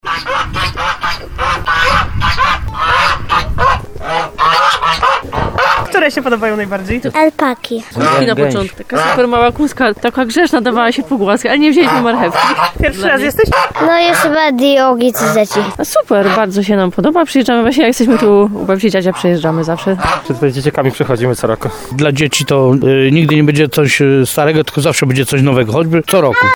Zwierzątka cieszą się dużym zainteresowaniem dzieci, ale również dorosłych. W ogrodzie wyraźnie słychać gęsi, ale również osiołka, kózki czy alpakę.